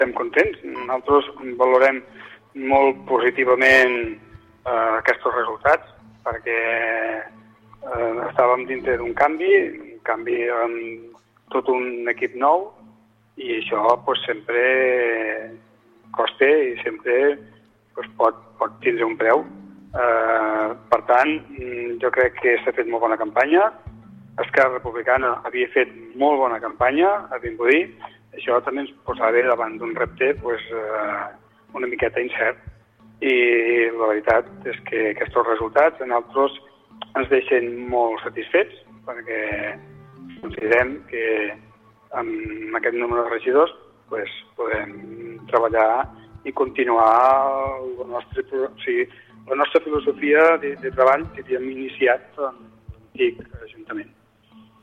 Joan Canela, en declaracions a l’EFMR durant el programa especial del 26-M, ha declarat que se senten contents i valoren positivament els resultats, tot i perdre un regidor, “estàvem dins d’un canvi amb tot un equip nou, i això sempre costa”, ha emfatitzat.